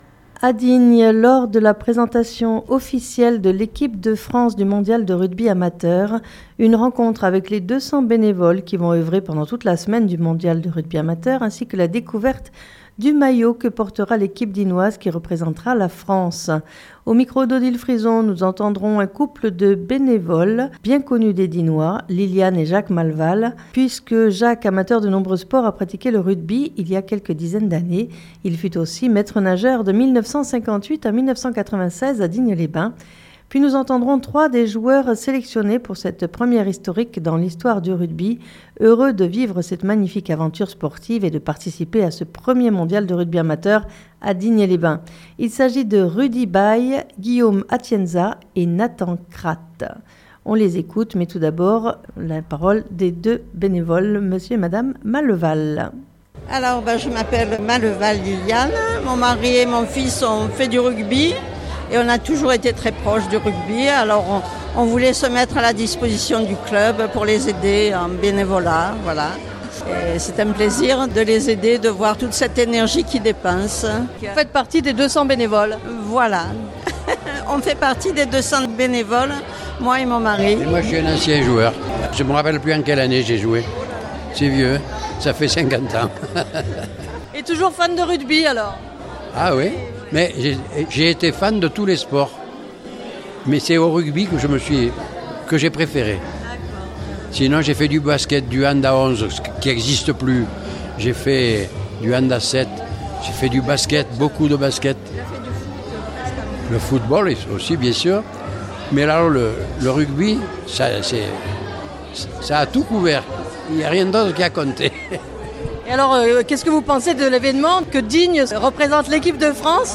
A Digne, lors de la présentation officielle de l'équipe de France du Mondial de Rugby Amateur !